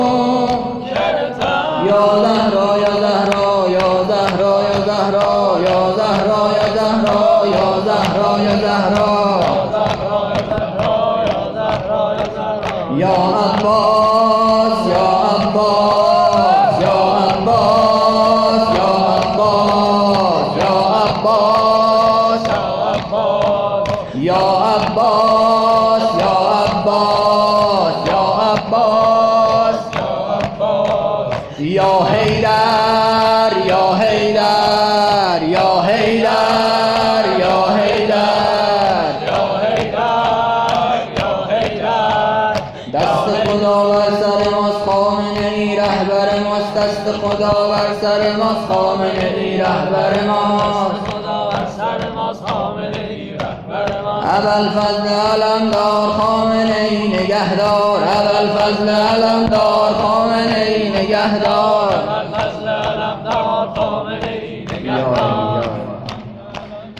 ذکر